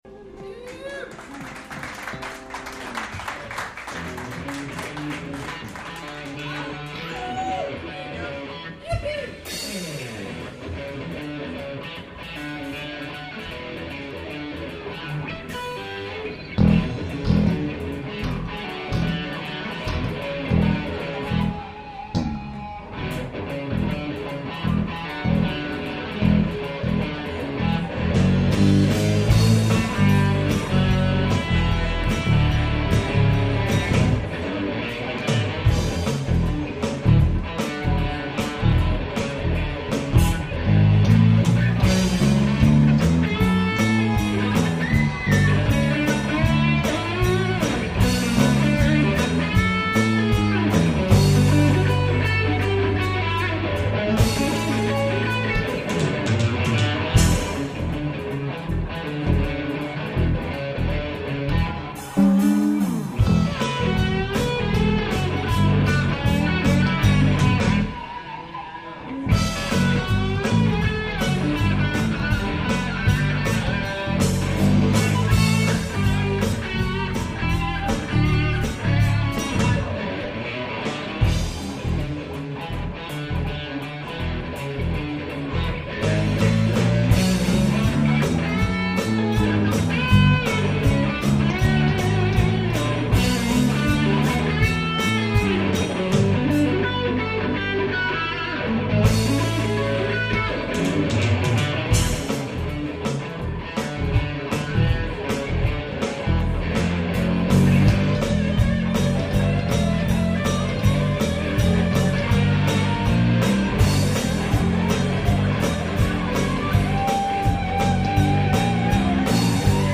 "Live" at the Metaphor Cafe in Escondido, CA
Guitar
Bass
Electronic Drums